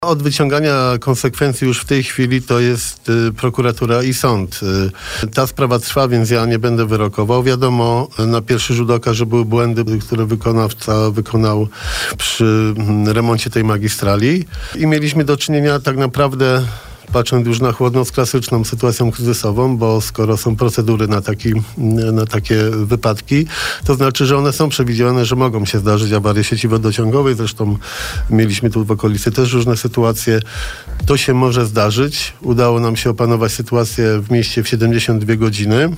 Wczoraj (29.12) porannym gościem Radia Bielsko był prezydent Bielska-Białej.